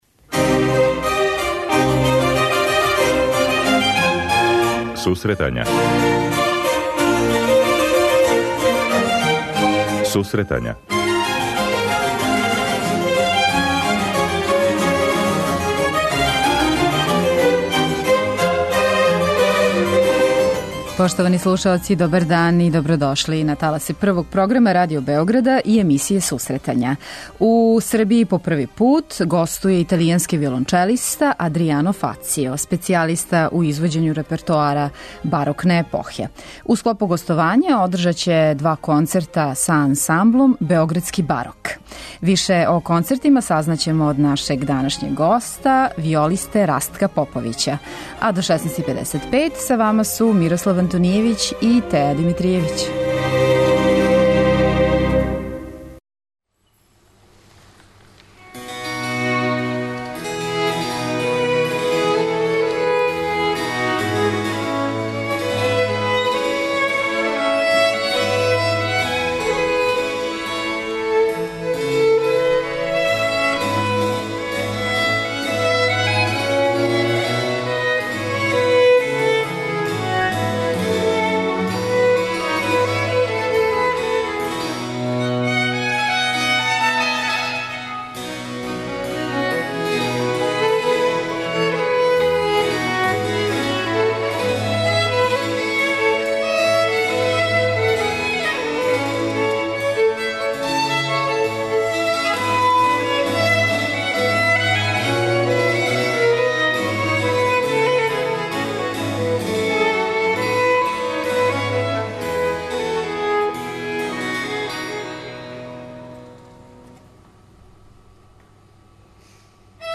преузми : 26.24 MB Сусретања Autor: Музичка редакција Емисија за оне који воле уметничку музику.